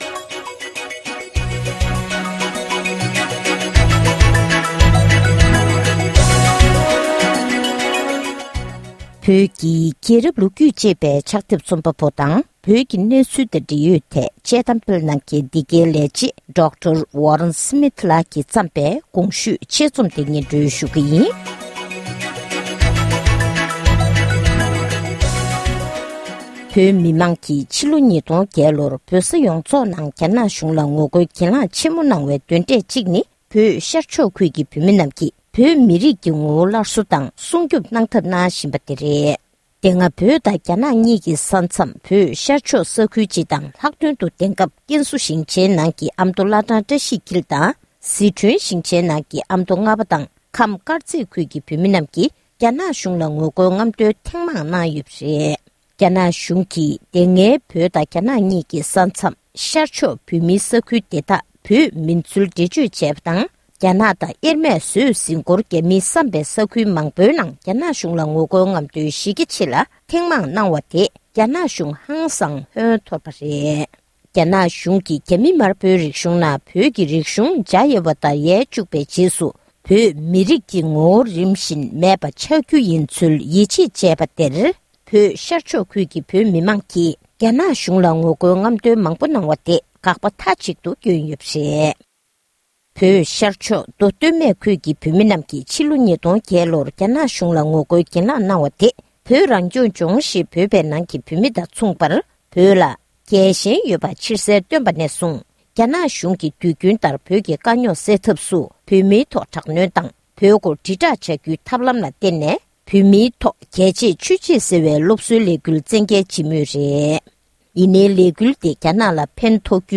བོད་ཤར་ཕྱོགས་ཁུལ་དུ་བོད་མིའི་ངོ་བོ་ཞེས་པའི་དཔྱད་རྩོམ།